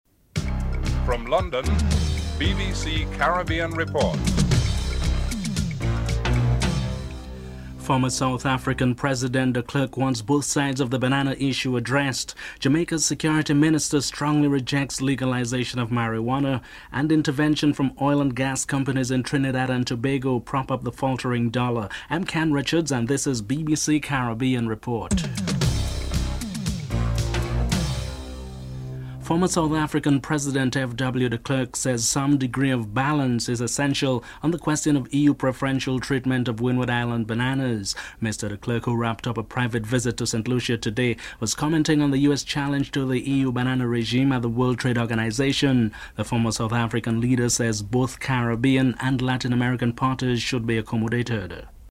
1. Headlines (00:00-00:29)
2. Former South African President de Klerk wants both sides of the banana issue addressed. Frederik Willlem de Klerk is interviewed (00:30-02:13)
6. Jamaica Security Minister strongly rejects legalisation of marijuana.